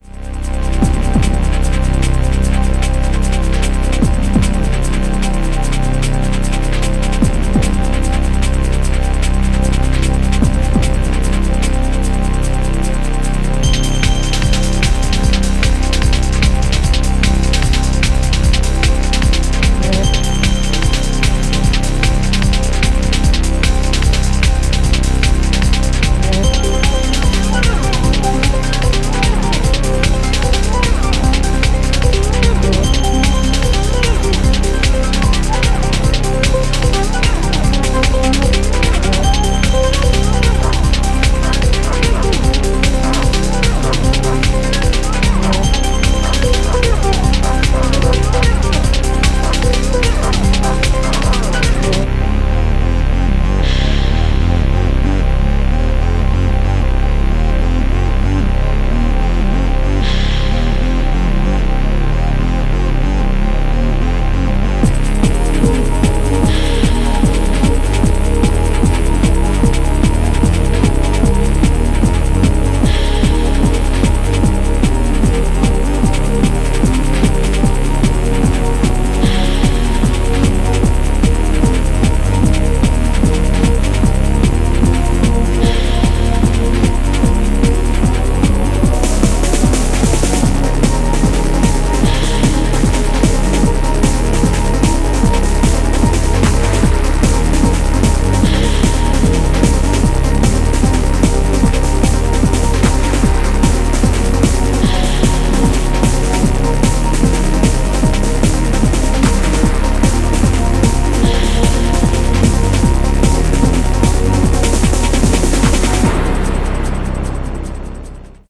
BPM150
Audio QualityPerfect (High Quality)
ripped straight from the original soundtrack cd.